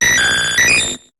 Cri de Porygon dans Pokémon HOME.